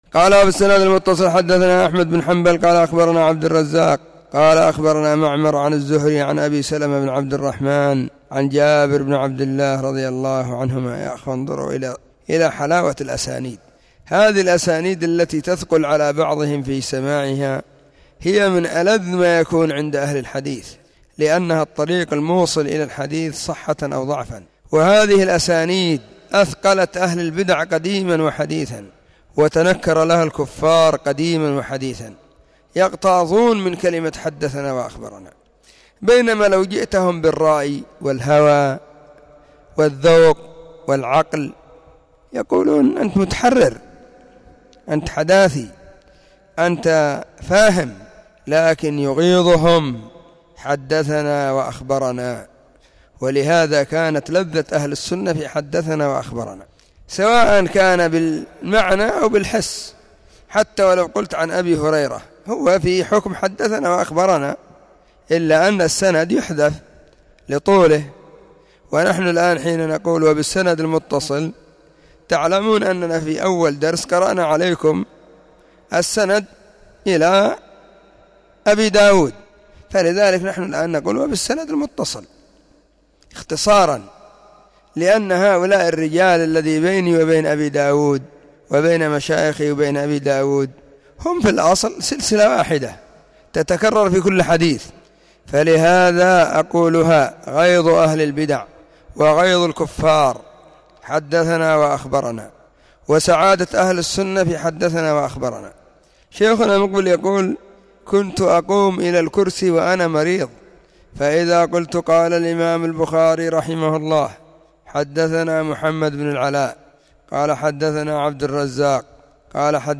📢 مسجد الصحابة – بالغيضة – المهرة – اليمن حرسها الله.